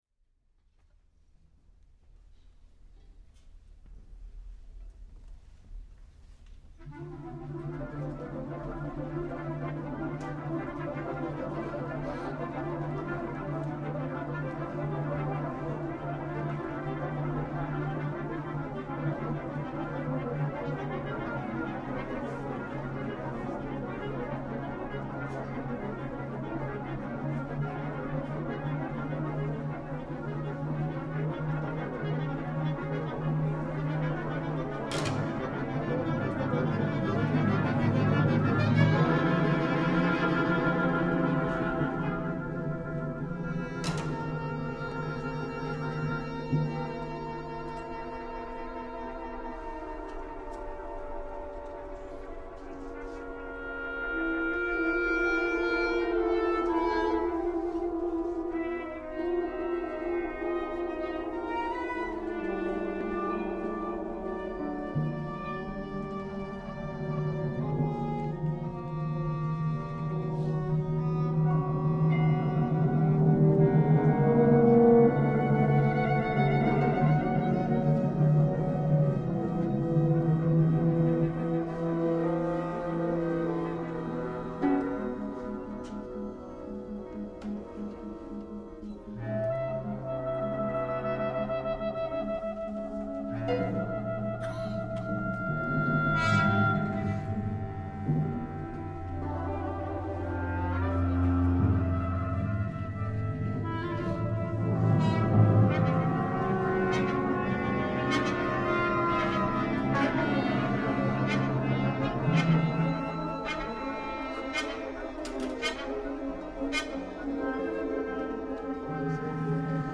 This is the world premiere version